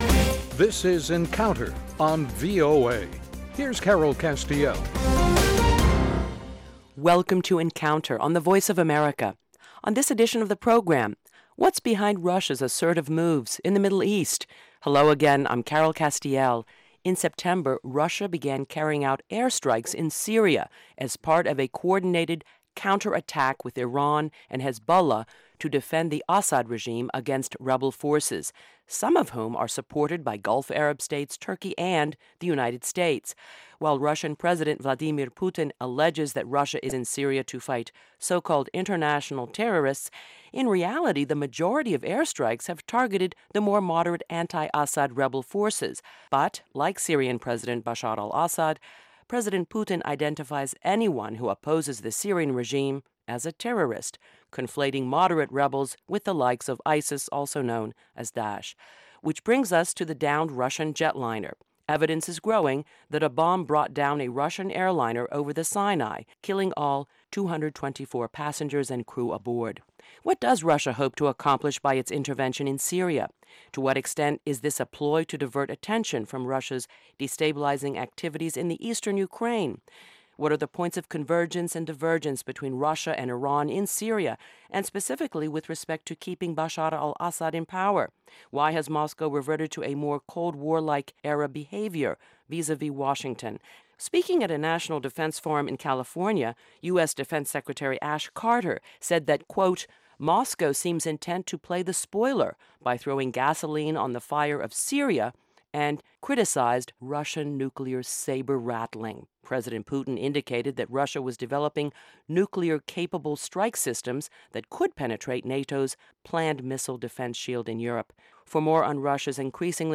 Issues that affect our lives and global stability are debated in a free-wheeling, unscripted discussion of fact and opinion.